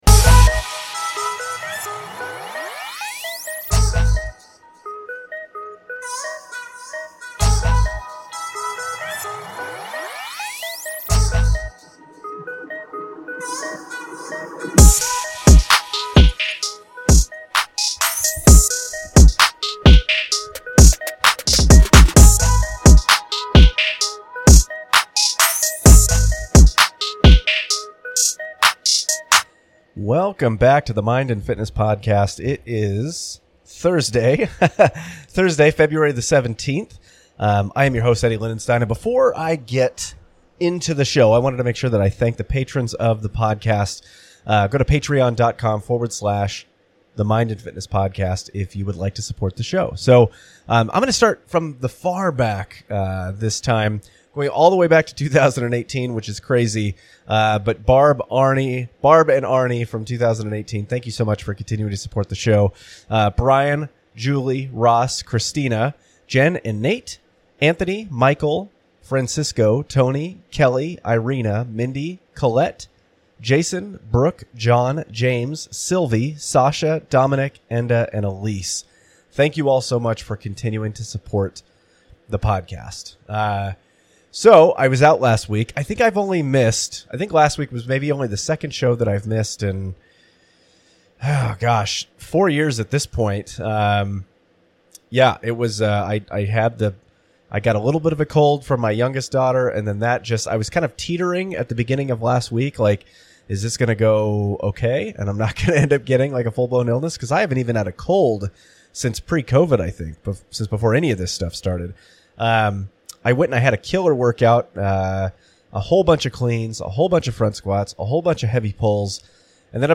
Several weeks ago I was interviewed